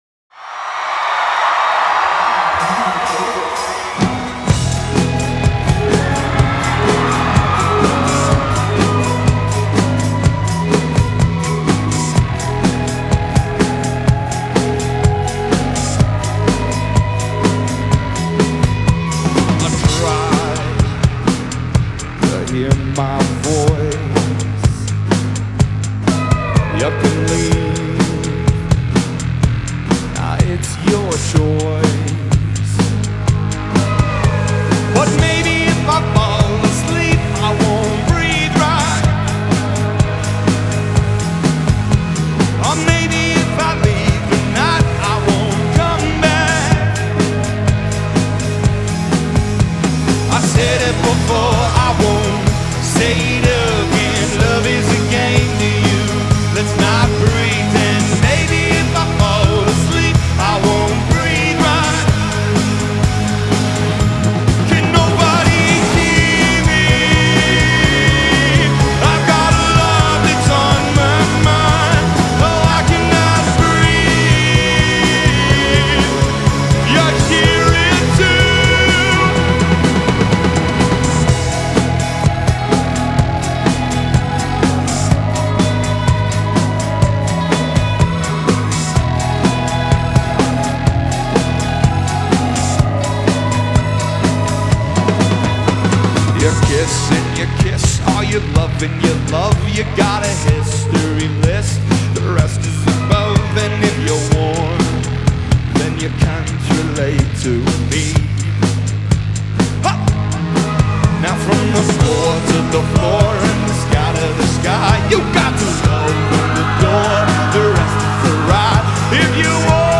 Genre : Alternative & Indie
Live From Red Rocks